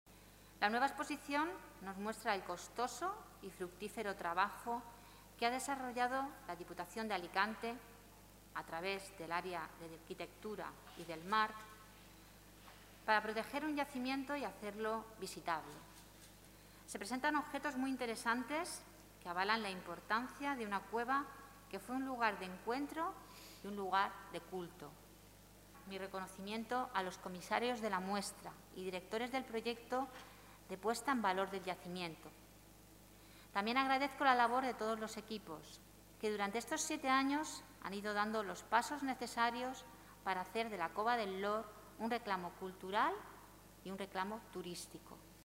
Corte-Julia-Parra.mp3